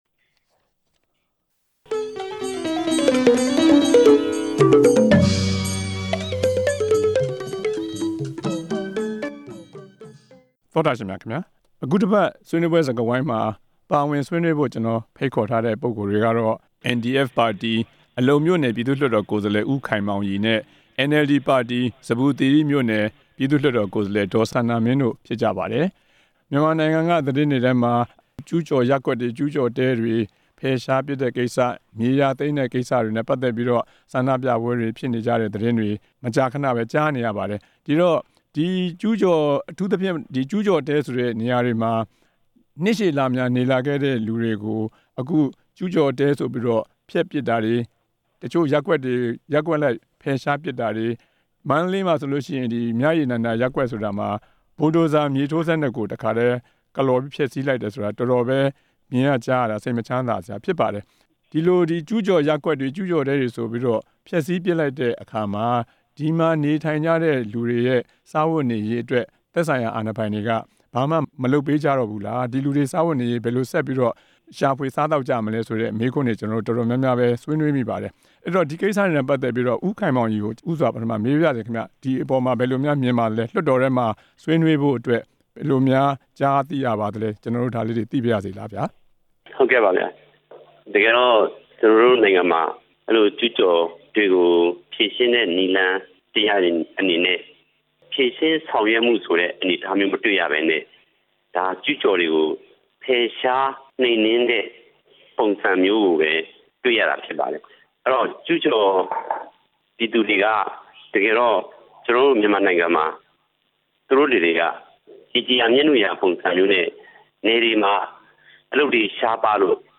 အကြမ်းဖက် ဖယ်ရှားခံရတဲ့ ကျူးကျော်ရပ်ကွက်တွေအကြောင်း ဆွေးနွေးချက်